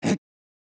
casting.wav